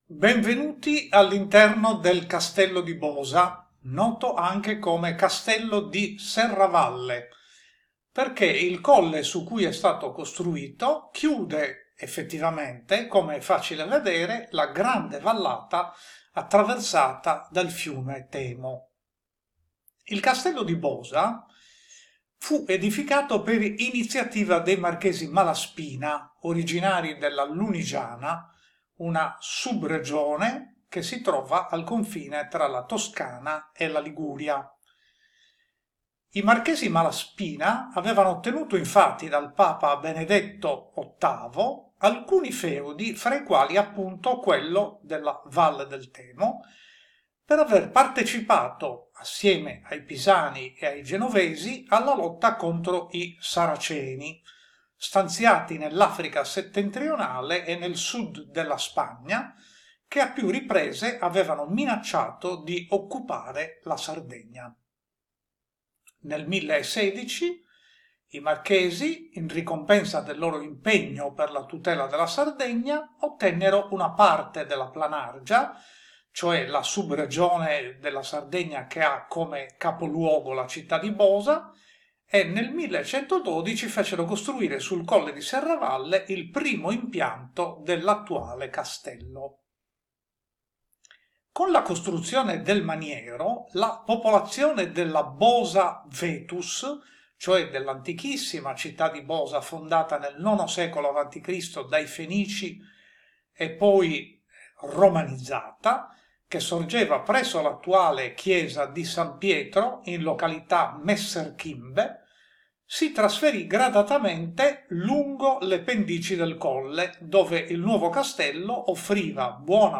Audioguide - Audioguides
Castello-di-Bosa-cenni-storici.mp3